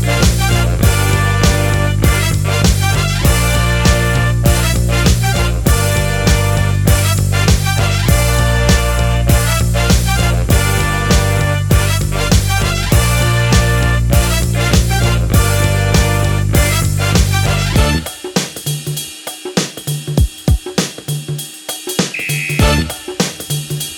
no Backing Vocals R'n'B / Hip Hop 3:59 Buy £1.50